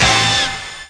68_12_stabhit-A.wav